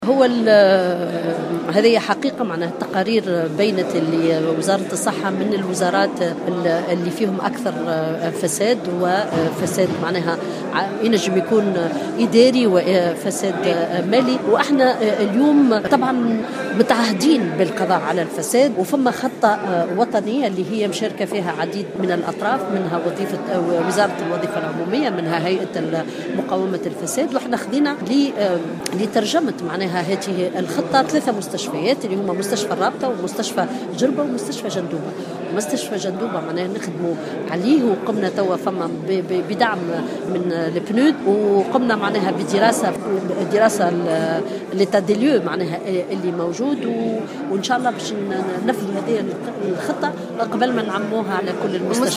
قالت وزير الصحة سميرة مرعي خلال حضورها اليوم الخميس 1 ديسمبر 2016 بمجلس نواب الشعب لمناقشة مشروع ميزانية الوزارة لسنة 2017 إن كل التقارير أظهرت أن وزارة الصحة هي من الوزارات التي فيها أكبر نسبة فساد قد يكون إداريا أو ماليا وفق تعبيرها.